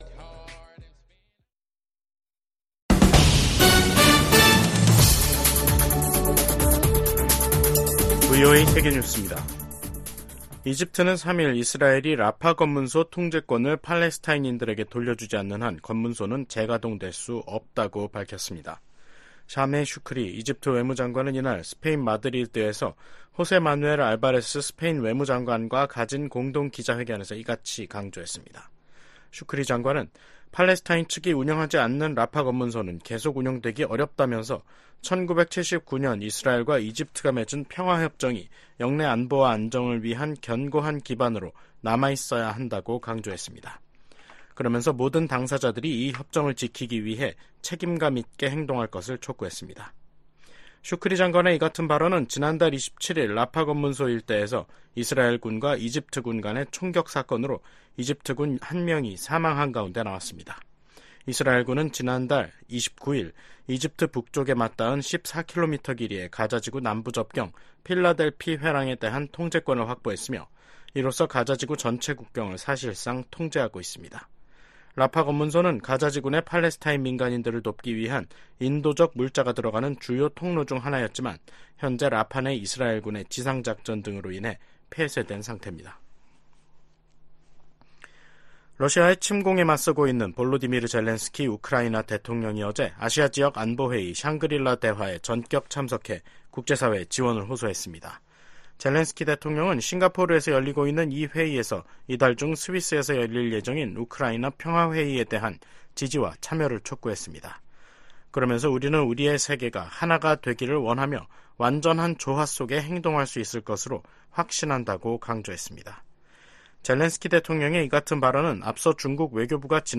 VOA 한국어 간판 뉴스 프로그램 '뉴스 투데이', 2024년 6월 3일 3부 방송입니다. 한국 정부는 9.19 군사합의 전체 효력을 정지하는 수순에 들어갔습니다.